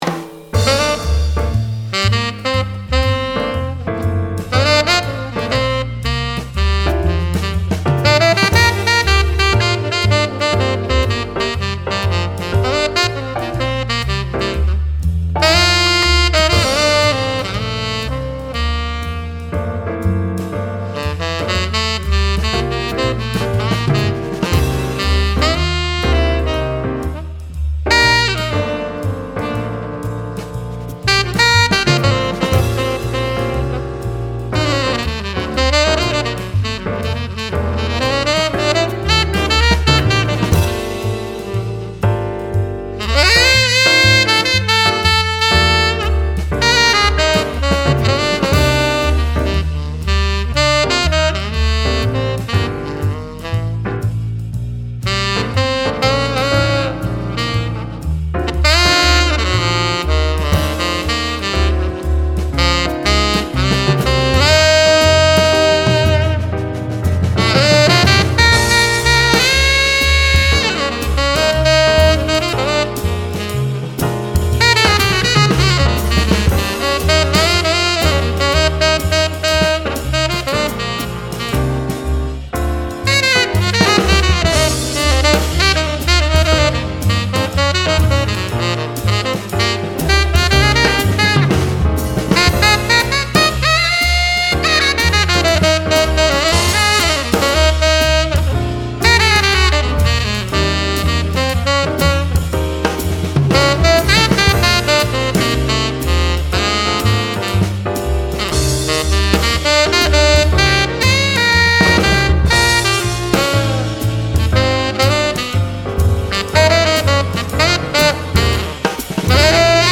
太く明るいサウンド: Metal HL2024は、太くパワフルなサウンドでありながら、同時に明るくクリアな音色も持ち合わせています。
バッフル：ハイバッフル
音色と特徴太い、エッジがある、フュージョン向け、雑味のある音色
テナー